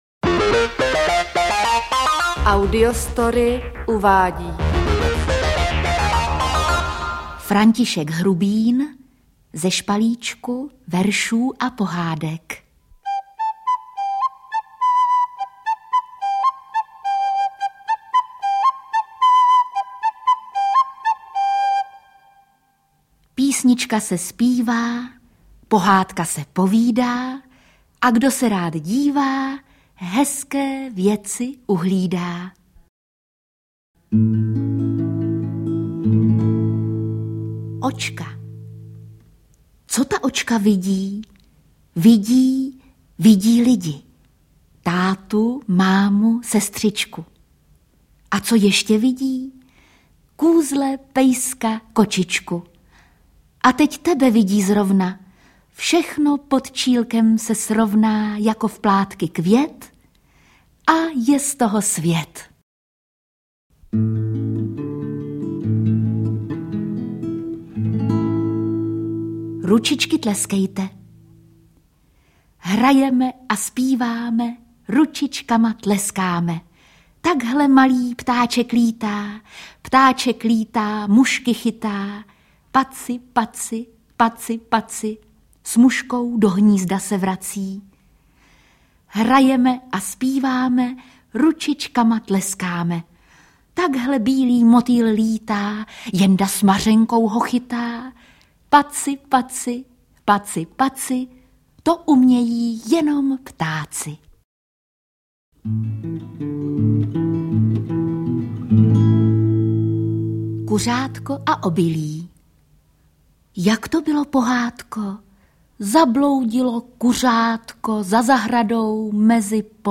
Interpret:  Jitka Molavcová